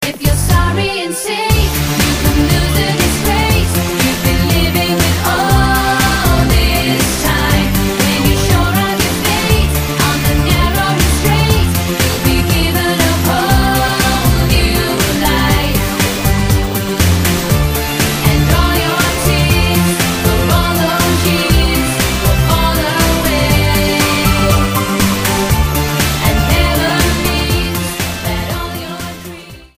STYLE: Pop
lightly funky